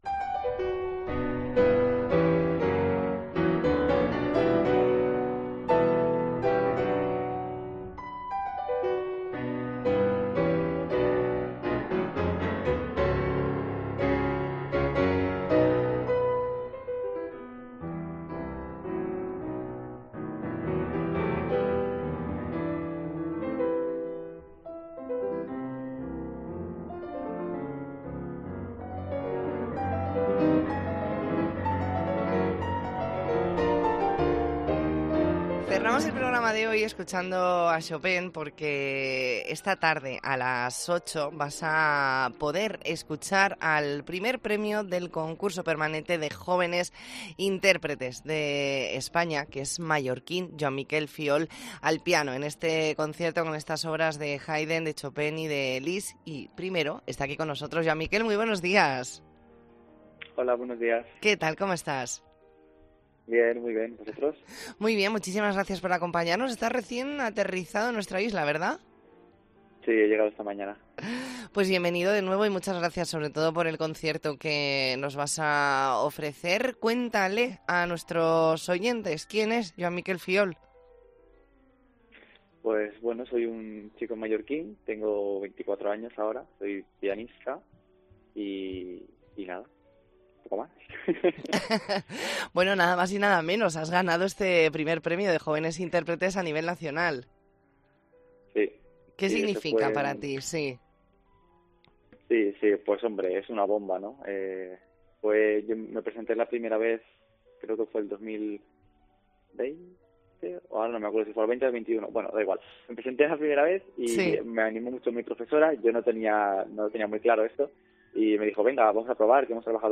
ntrevista en La Mañana en COPE Más Mallorca, viernes 15 de diciembre de 2023.